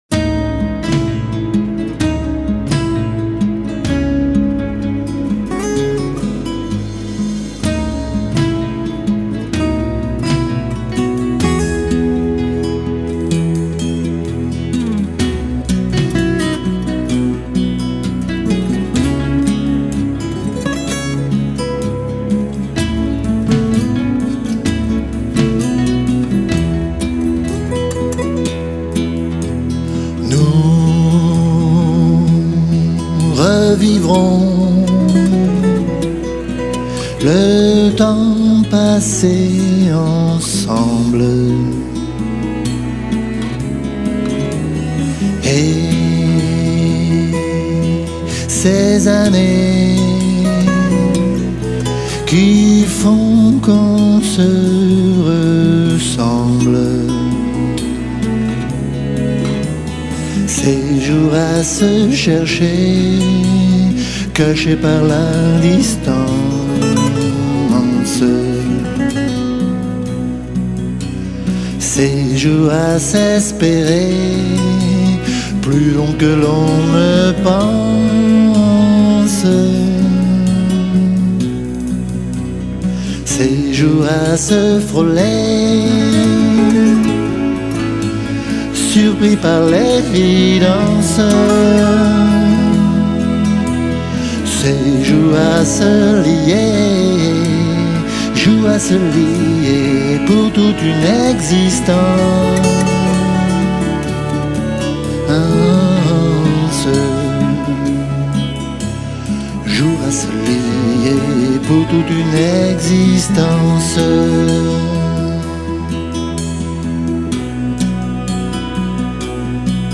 une ambiance plus mélancolique, plus blues, plus bossa